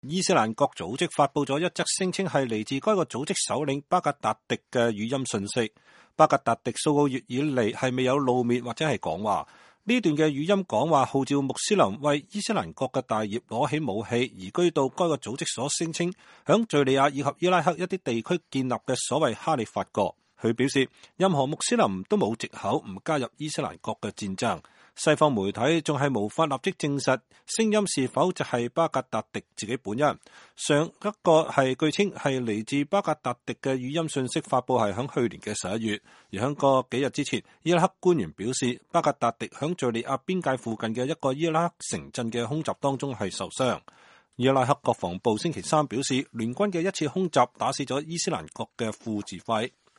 伊斯蘭國組織發布了一則聲稱是來自該組織首領阿布.巴克爾.巴格達迪的語音信息。